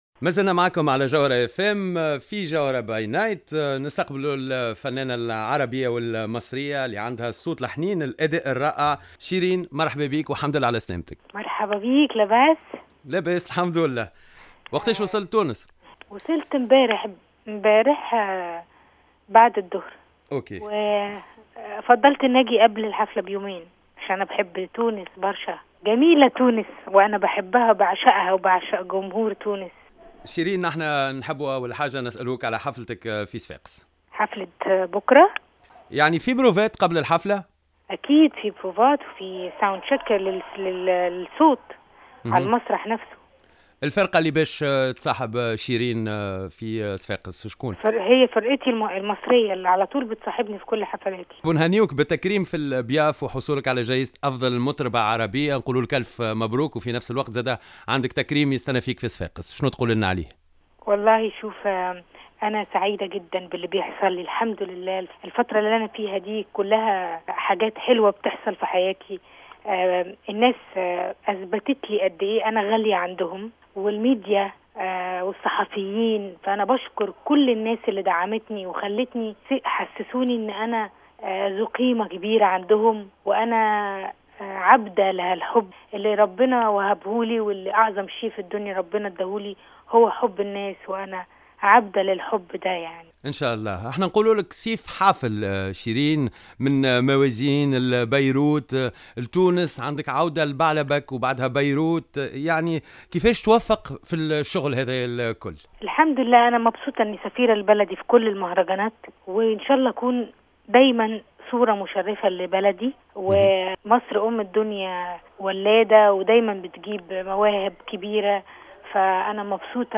حوار حصري مع النجمة شيرين تحميل المشاركة علي